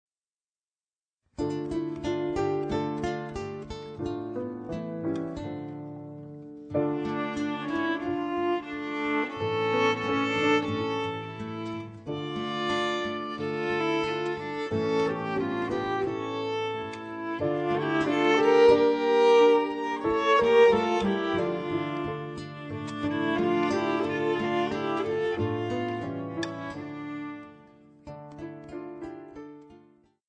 32 Weihnachtslieder aus verschiedenen Ländern für 1-2 Violen
Besetzung: 1-2 Violen mit CD